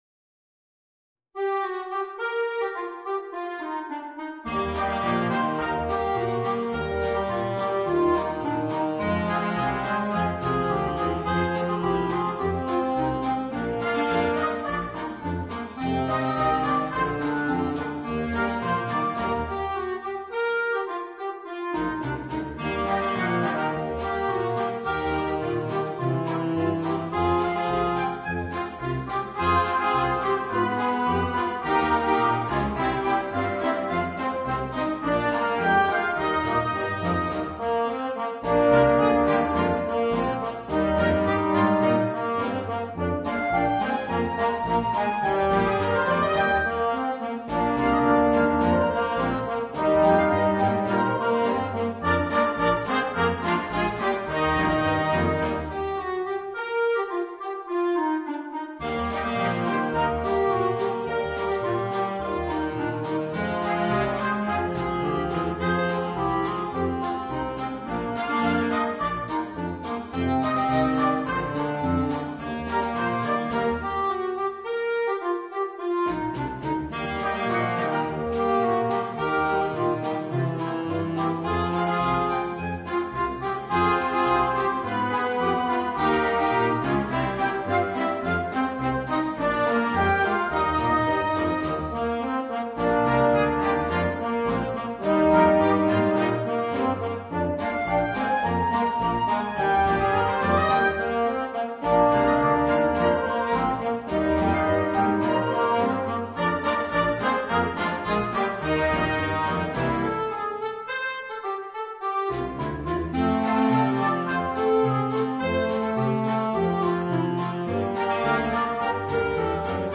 Marcia fox trot
MARCE DA SFILATA
MUSICA PER BANDA